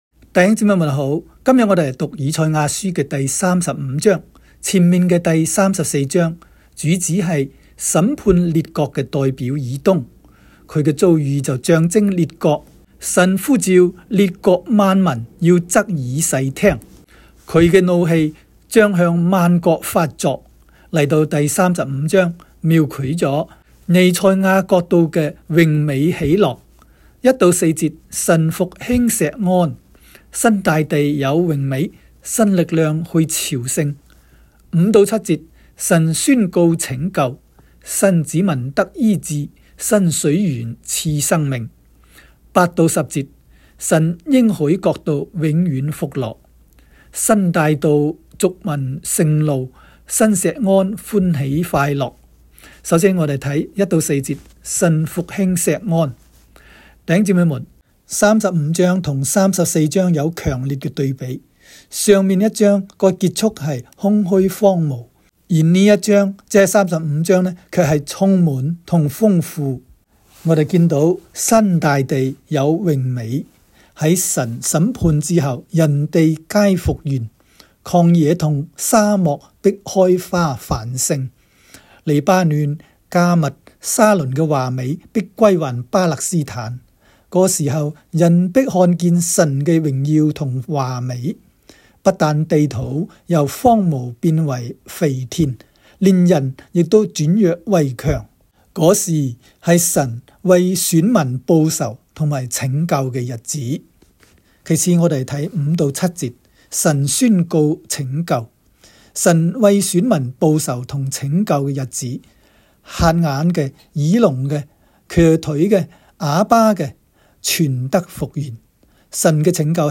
赛35（讲解-粤）.m4a